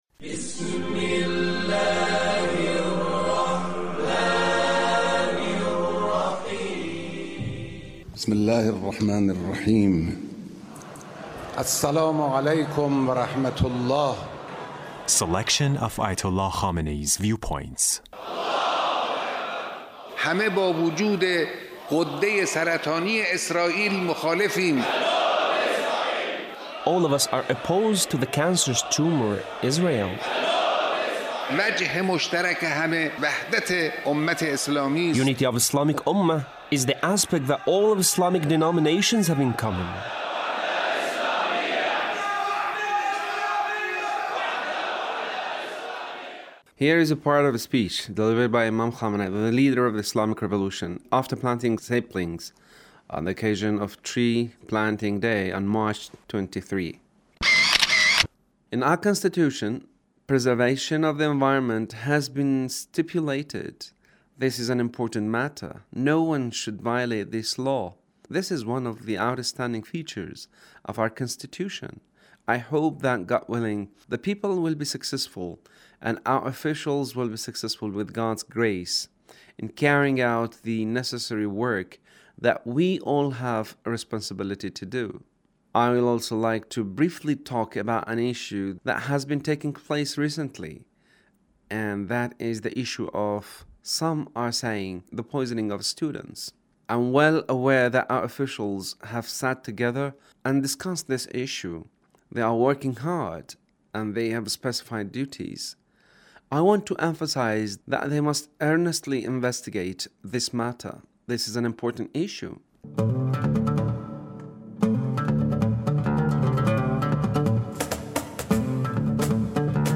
Leader's Speech on Tree Planting Day